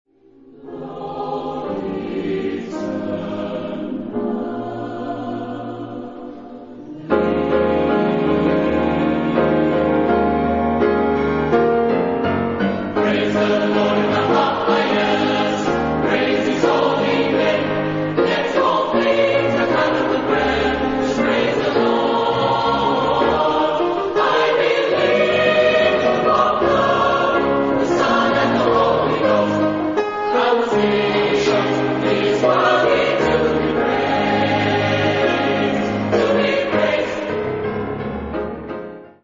Genre-Style-Form: Gospel ; Sacred ; Hymn (sacred)
Mood of the piece: dynamic
Type of Choir: SATB  (4 mixed voices )
Instrumentation: Piano  (1 instrumental part(s))
Tonality: A flat major